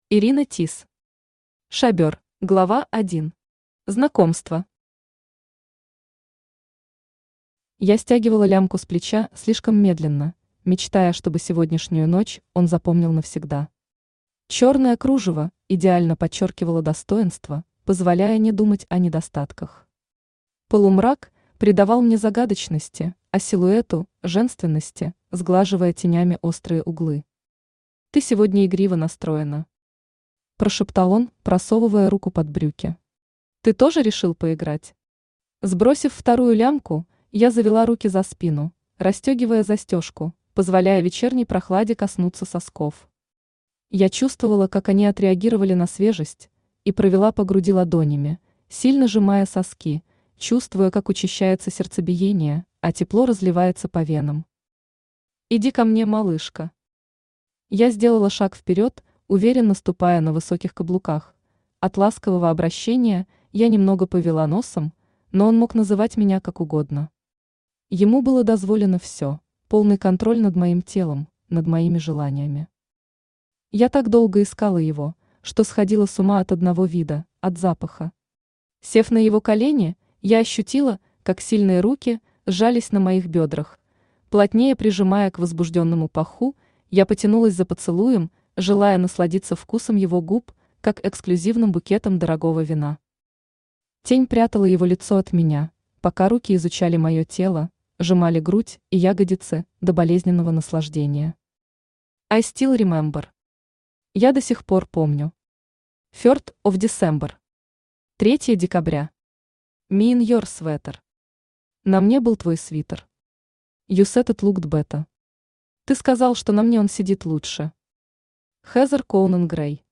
Aудиокнига Шабёр Автор Irina Tis Читает аудиокнигу Авточтец ЛитРес.